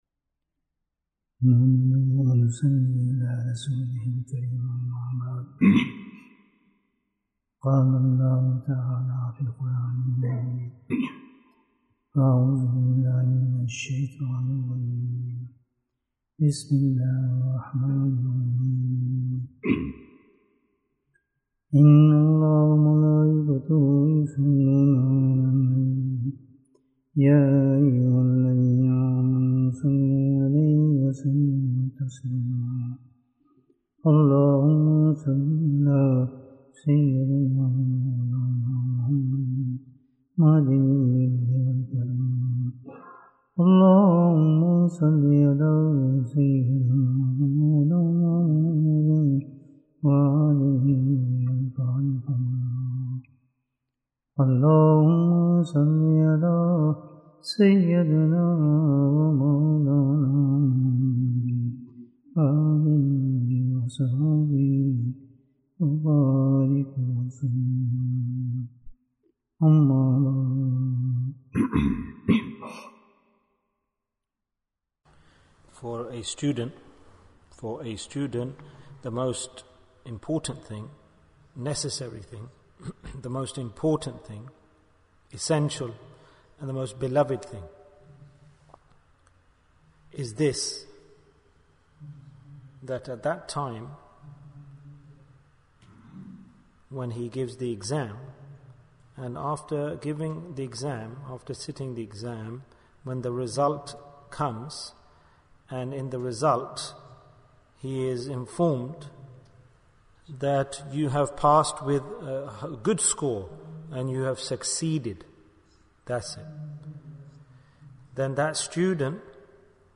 The Door of Success Bayan, 46 minutes18th August, 2022